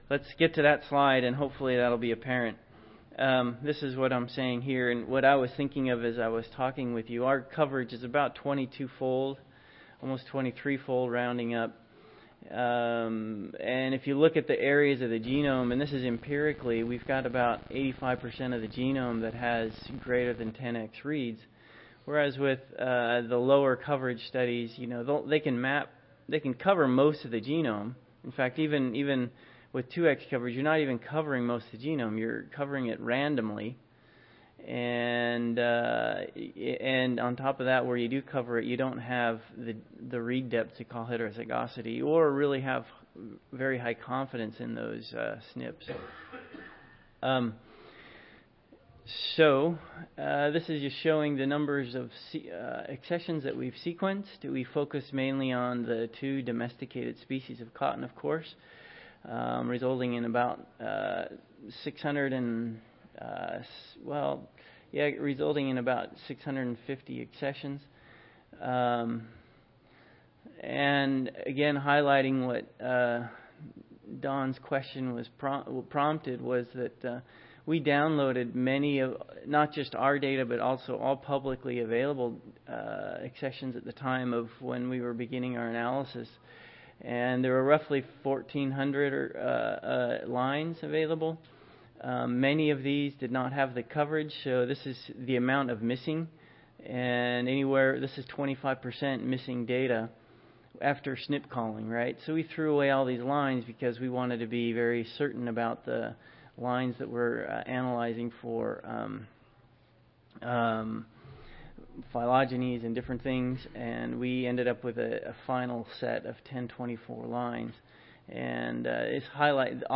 Recorded Presentation
See more of: Cotton Improvement - Wednesday Morning Plenary Lecture See more of: Cotton Improvement Conference